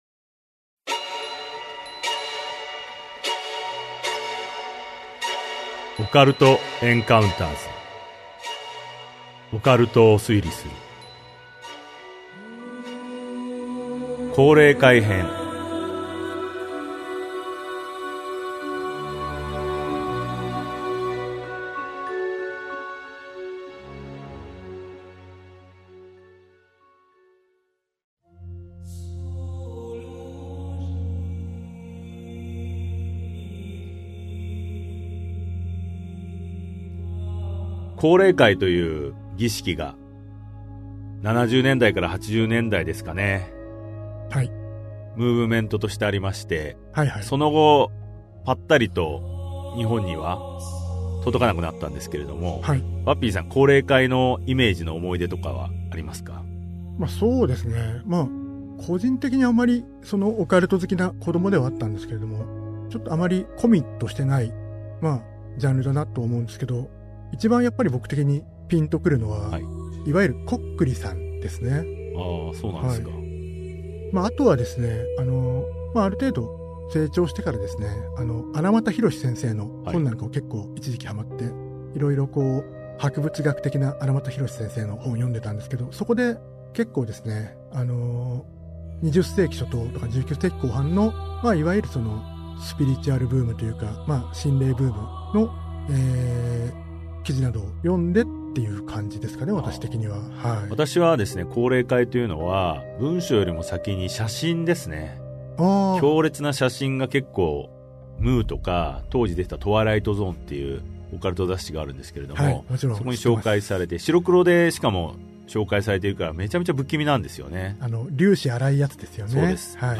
[オーディオブック] オカルト・エンカウンターズ オカルトを推理する Vol.10 降霊会編